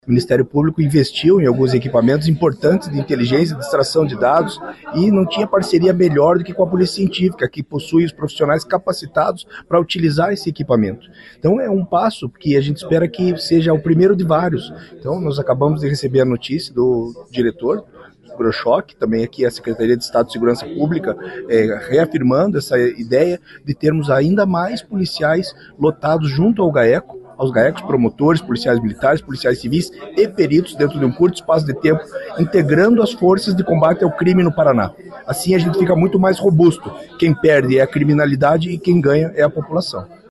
O procurador-geral de Justiça, Francisco Zanicotti, destacou a importância do compartilhamento de informações e integração entre as forças de segurança do Paraná.